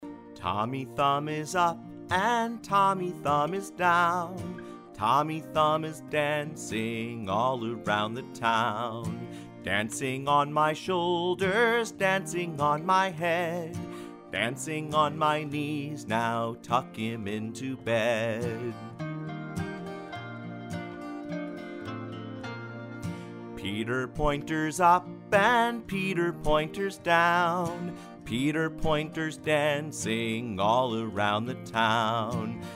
Children's Nursery Rhyme Song Lyrics and Sound Clip